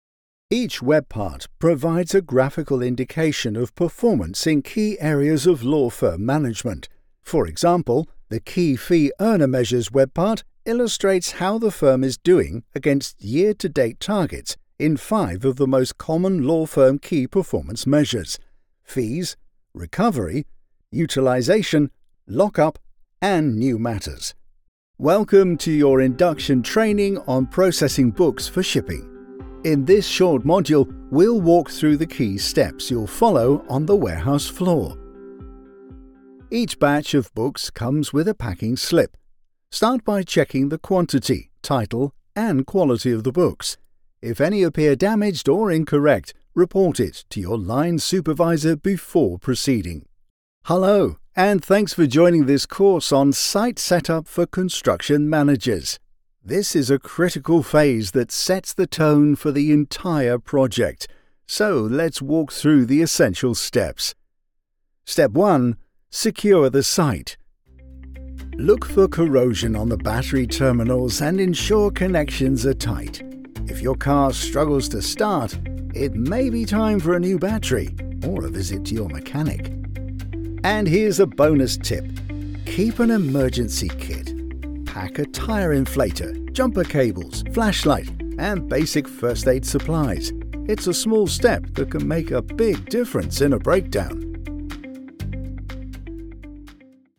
Male
Older Sound (50+)
With over a decade of experience, I have a warm, trustworthy, and versatile British male voice with a natural RP accent.
Broadcast-quality audio from a professional home studio with fast turnaround and seamless delivery.
E-Learning Reel 2025